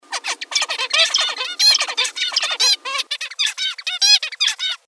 Zebra finch songs
Listen to clips of zebra finch songs recorded by Zurich University neurologists